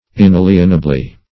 Meaning of inalienably. inalienably synonyms, pronunciation, spelling and more from Free Dictionary.
inalienably.mp3